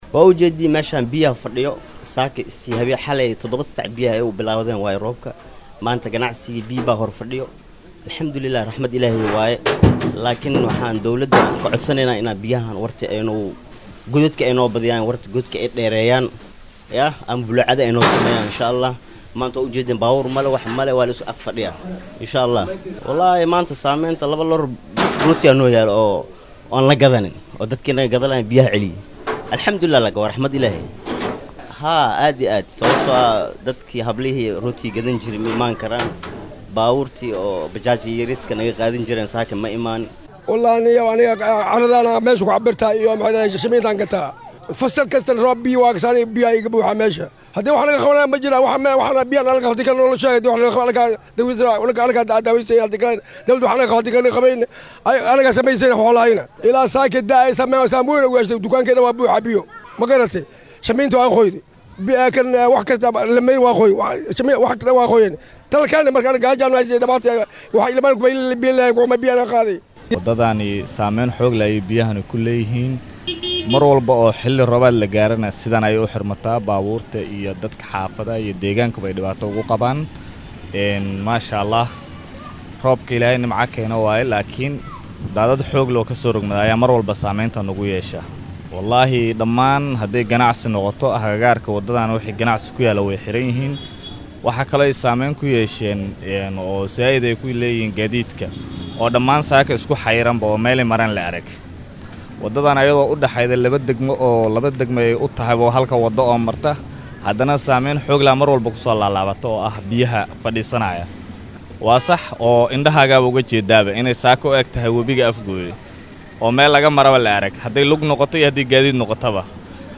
Ganacsatada goobahan ayaa dowladda ka codsaday inay arrintan wax ka qabato. Haddaba qaar ka mid ah ganacsatada ayaa warbaahinta u warramay